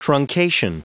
Prononciation du mot truncation en anglais (fichier audio)